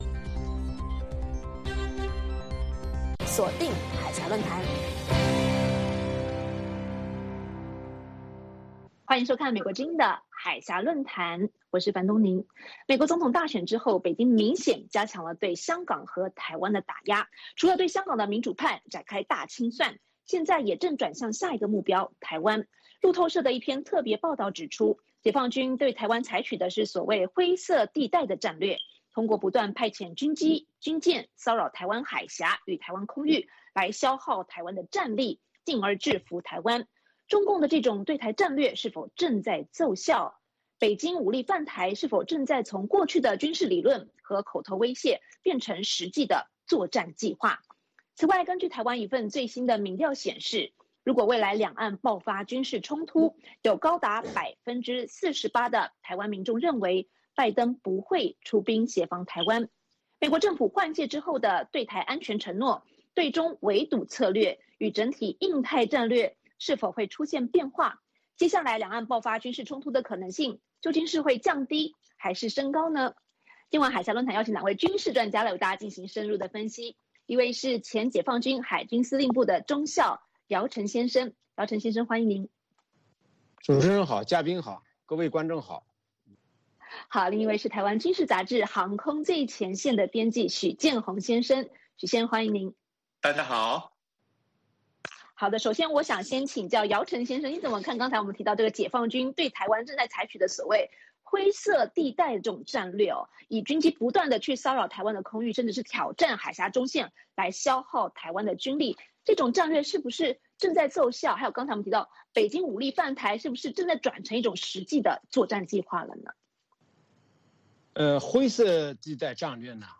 《海峡论谈》节目邀请华盛顿和台北专家学者现场讨论政治、经济等各种两岸最新热门话题。